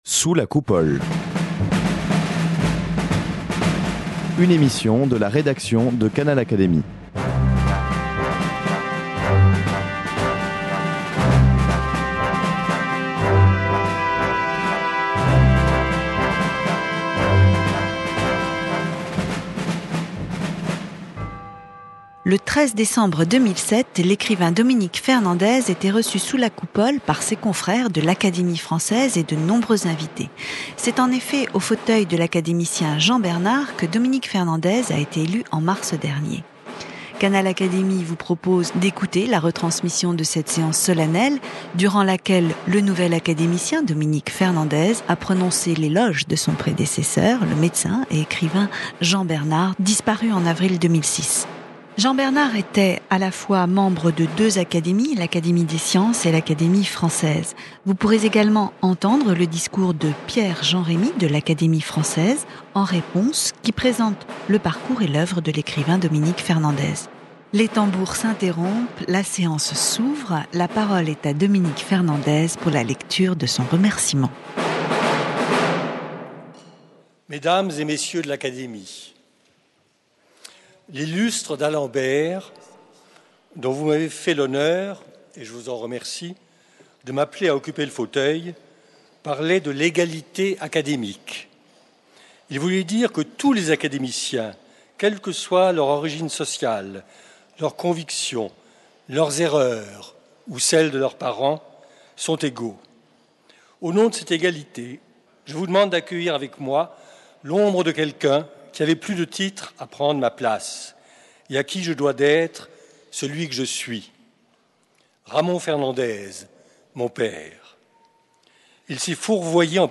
Élu quelques mois auparavant, en mars 2007 à l’Académie française, au fauteuil de Jean Bernard, il prononça l’éloge de son prédécesseur, le médecin et romancier Jean Bernard. Son confrère Pierre-Jean Rémy lut en réponse, un discours qui présente le nouvel académicien, Dominique Fernandez. Canal Académie vous propose d’écouter la retransmission de cette séance solennelle.